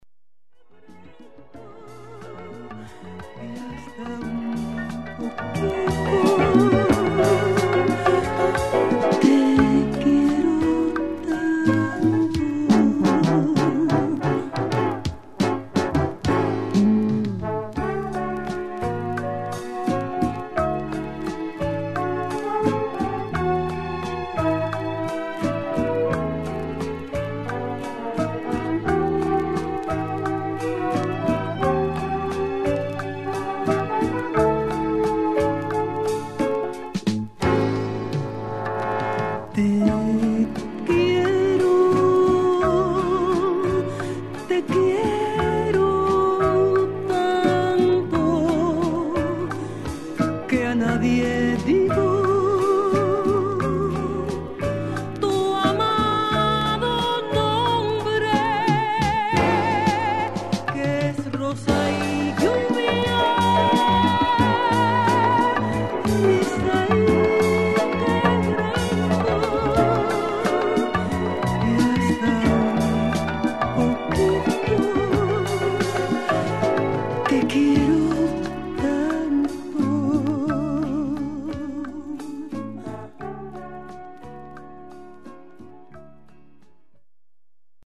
女性シンガー
CONDITION見た目VG+,音はVG+(+)/VG++ (VINYL/JACKET)
1960年代から活躍している、キューバの女性シンガー/女優